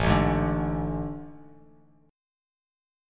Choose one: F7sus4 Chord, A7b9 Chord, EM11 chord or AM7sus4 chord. AM7sus4 chord